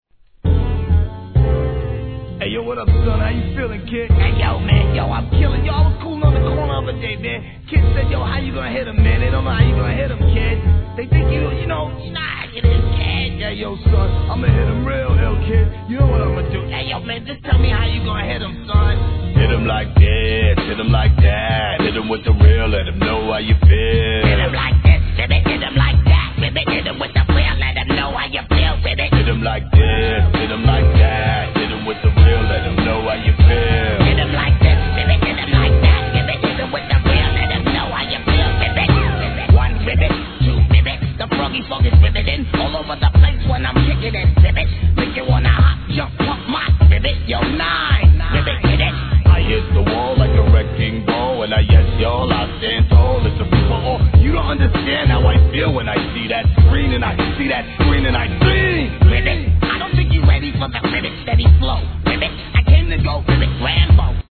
HIP HOP/R&B
お馴染みの濁声RAPは未だ根強い人気!